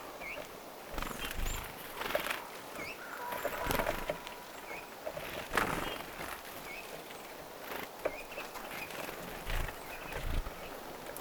Yhdellä punatulkulla oli erilainen äänityyppi: tuit?
punatulkku ääntelee tuit
punatulkku_aantelee_tuit.mp3